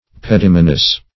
Search Result for " pedimanous" : The Collaborative International Dictionary of English v.0.48: Pedimanous \Pe*dim"a*nous\, a. [See Pedimana .]